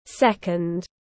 Giây tiếng anh gọi là second, phiên âm tiếng anh đọc là /ˈsek.ənd/
Second /ˈsek.ənd/